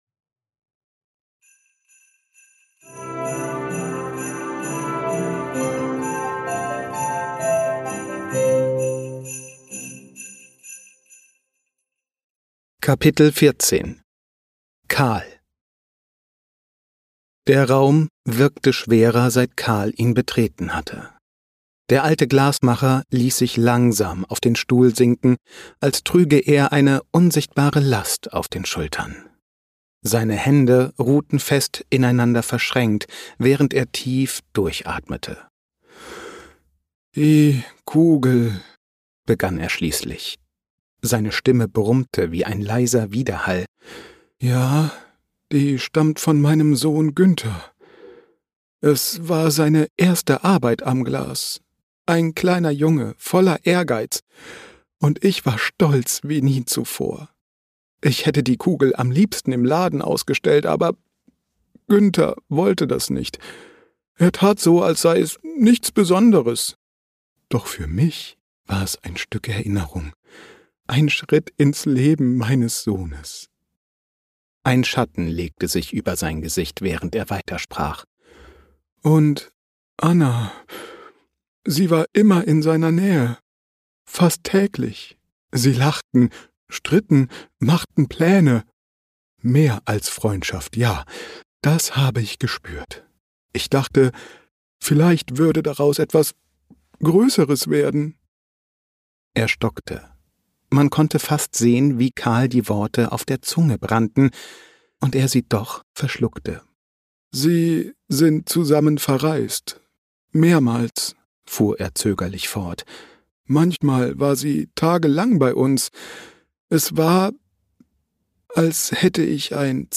Lass dich von acht verzaubernden Stimmen in die